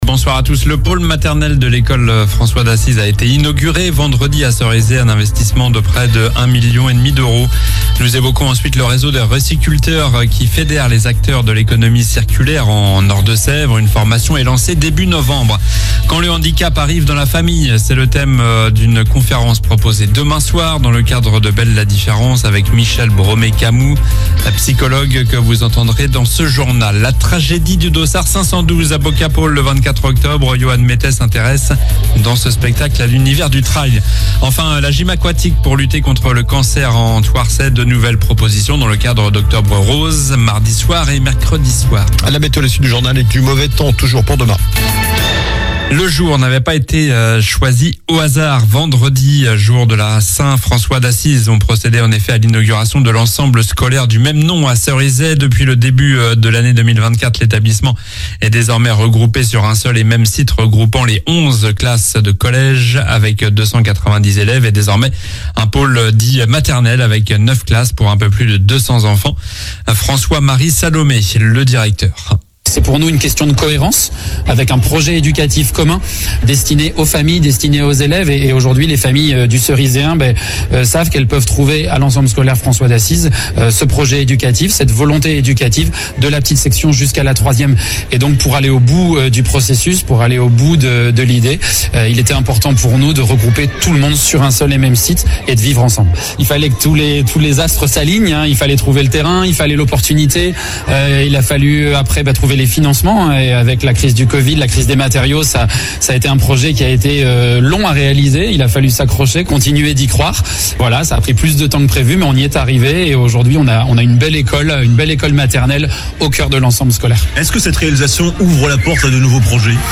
Journal du lundi 7 octobre (soir)